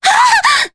Veronica-Vox_Damage_jp_03.wav